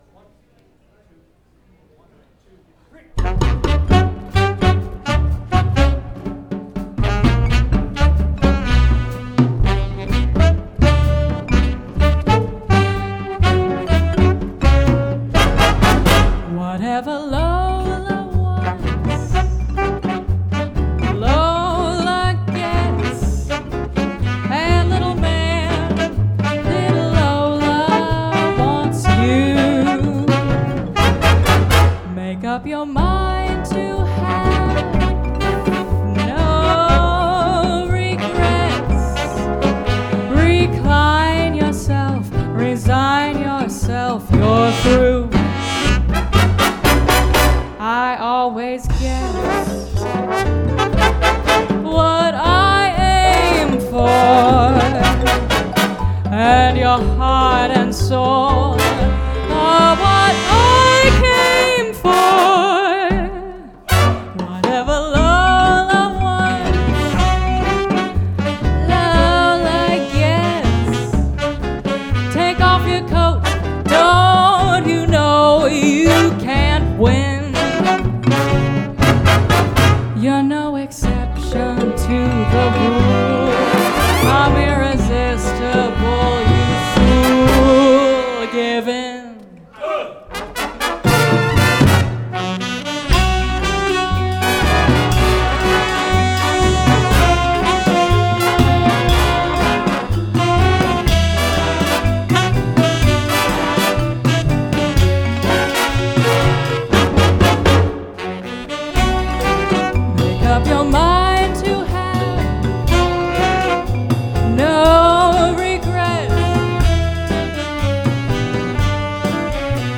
-- This is an unedited MP3 recording.
* All 14 x horns: Shure Beta 98 H/C's
* Overheads on Kit: 2 x Shure SM81's